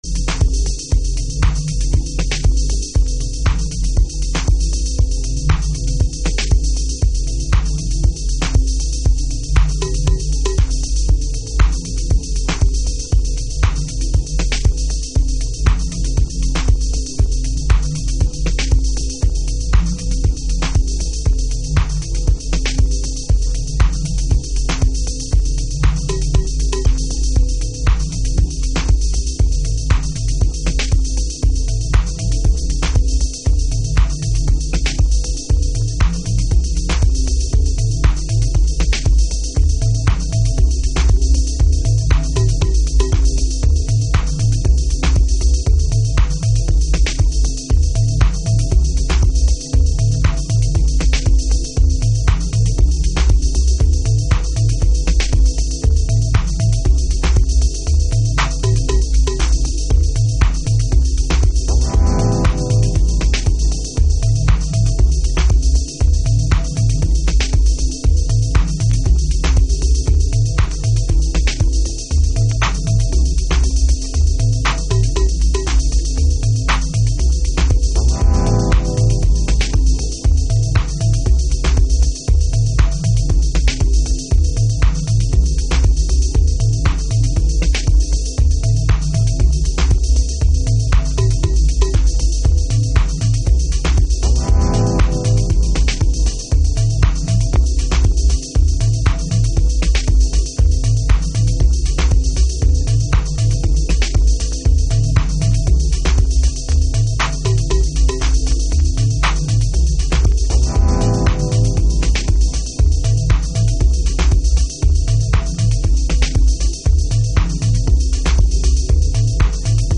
House / Techno
FORMAT - 12inch